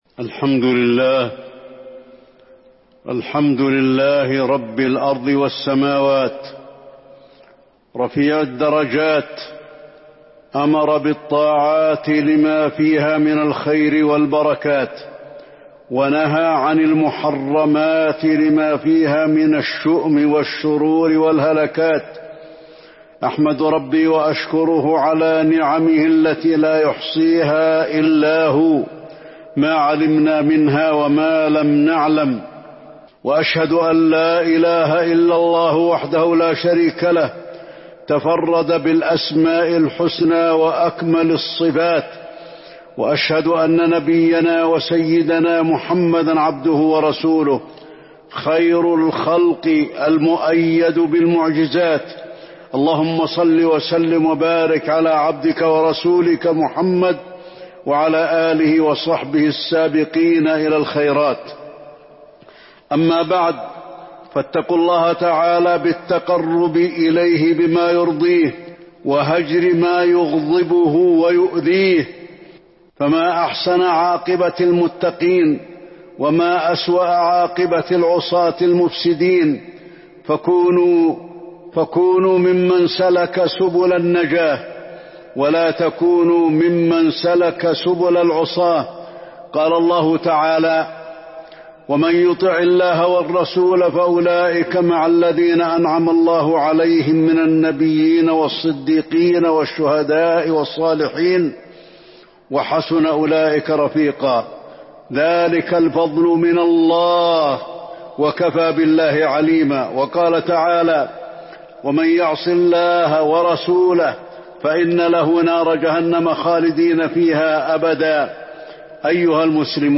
تاريخ النشر ١ ذو القعدة ١٤٤٢ هـ المكان: المسجد النبوي الشيخ: فضيلة الشيخ د. علي بن عبدالرحمن الحذيفي فضيلة الشيخ د. علي بن عبدالرحمن الحذيفي الكون حكم وأحكام